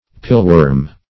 pileworm - definition of pileworm - synonyms, pronunciation, spelling from Free Dictionary Search Result for " pileworm" : The Collaborative International Dictionary of English v.0.48: Pileworm \Pile"worm`\, n. (Zool.)